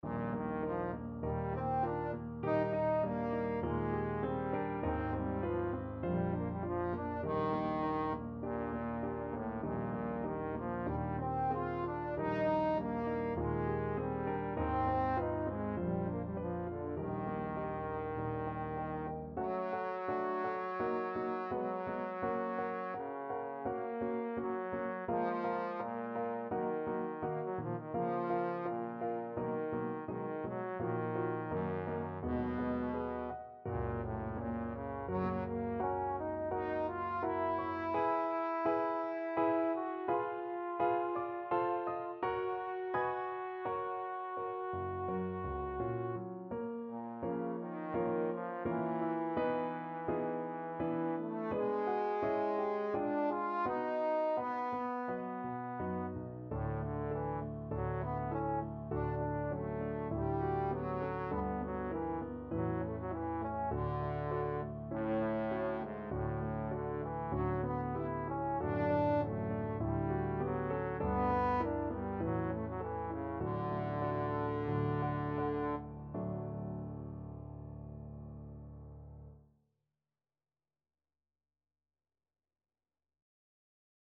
Moderato
4/4 (View more 4/4 Music)
Classical (View more Classical Trombone Music)